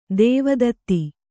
♪ dēva datti